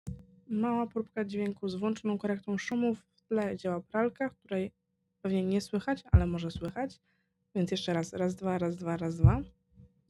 Tłumi jednostajny szum tła i część odgłosów otoczenia, ale nie próbuje robić z domowego pokoju profesjonalnego studia.
Różnica jest słyszalna, choć nie przesadzona.
Dźwięk z korektą szumów